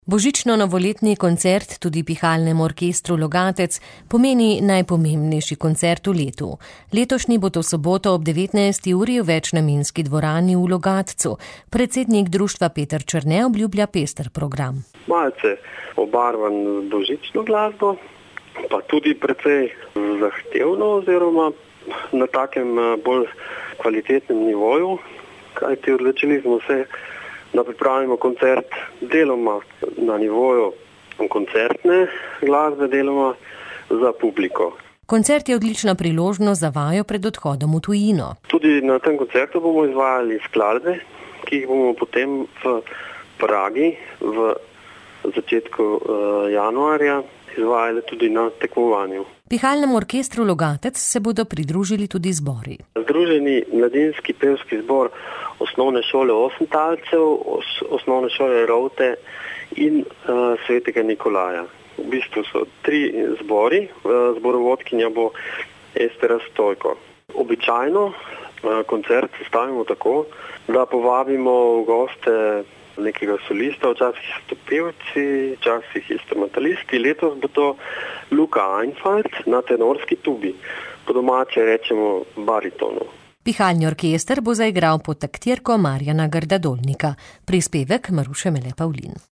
P232-79_novoletni_koncert_godbe_logatec.mp3